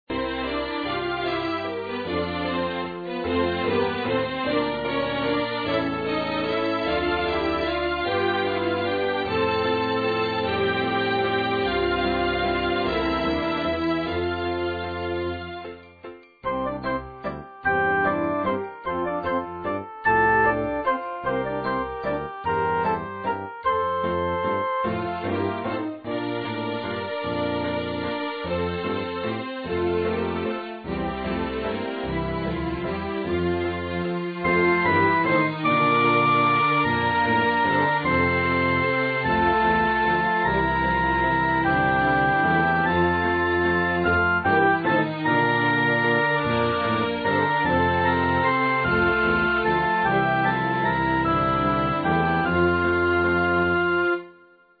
overture